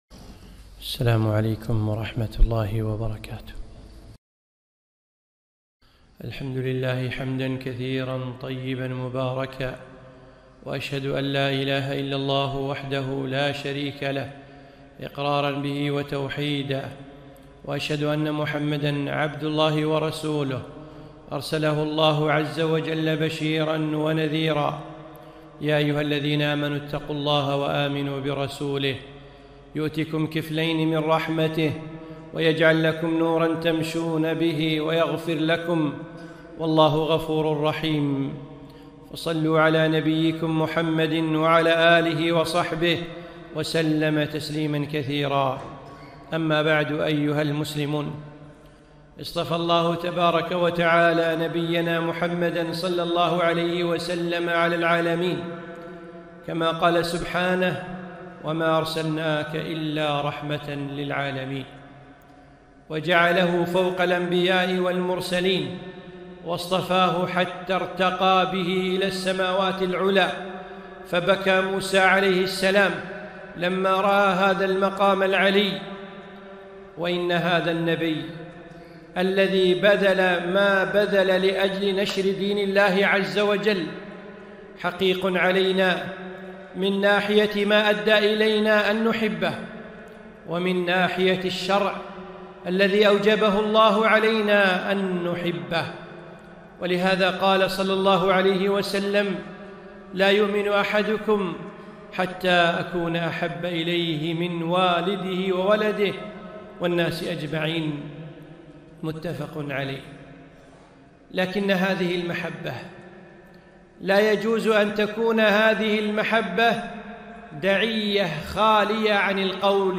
خطبة - هكذا تكون محبة النبي ﷺ